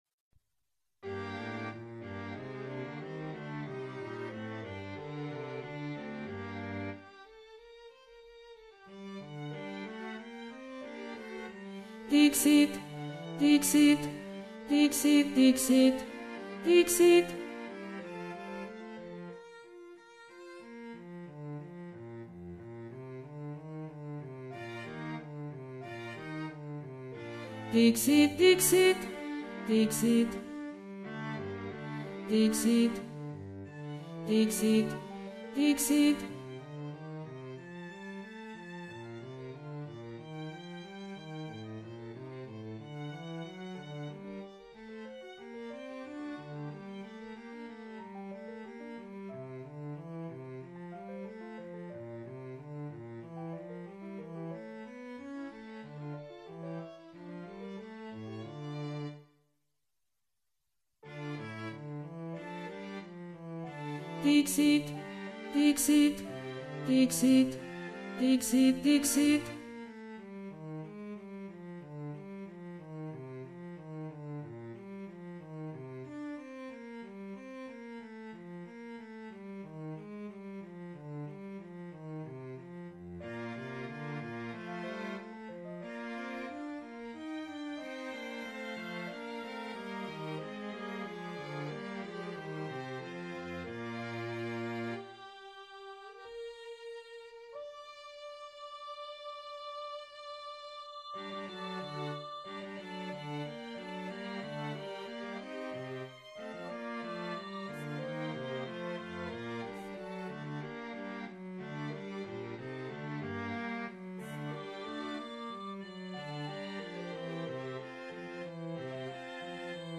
Altos
Emphasised voice and other voices
Dixit-Dominus-Alto-2.mp3